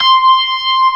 55O-ORG22-C6.wav